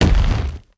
troll_commander_walk_right.wav